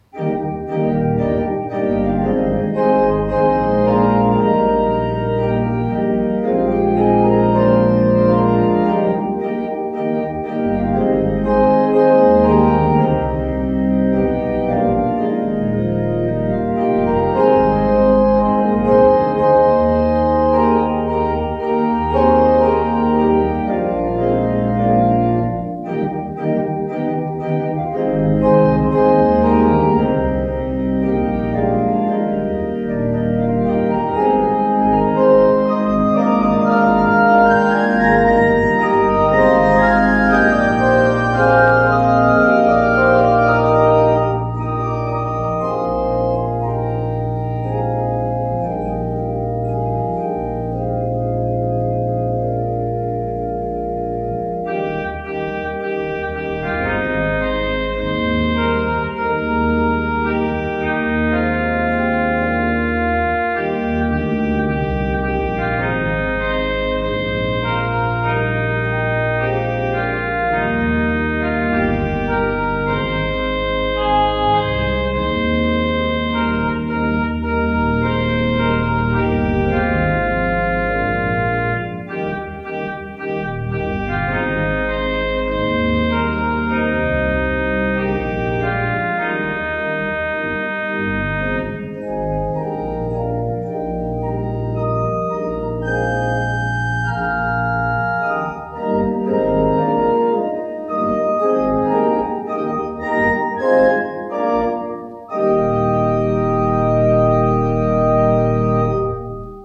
By Organist/Pianist